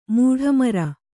♪ mūḍha mara